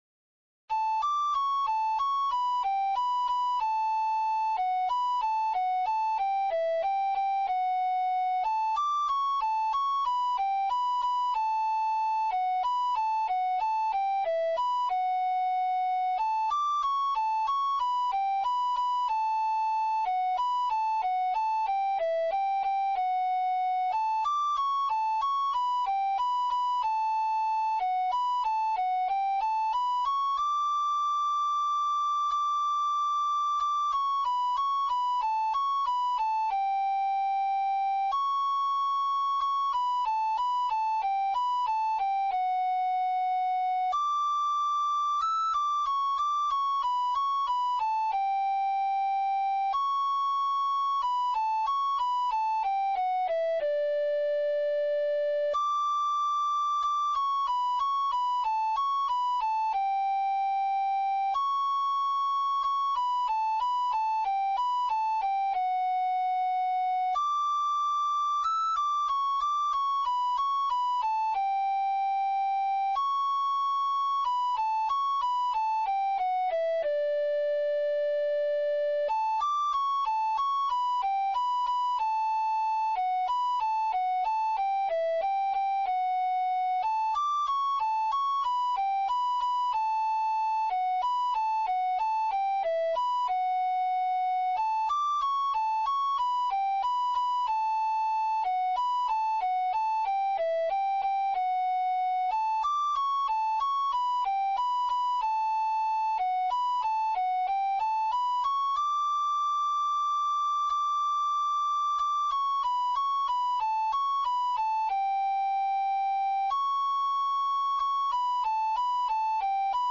Valses – Pezas para Gaita Galega
Valses
Primeira voz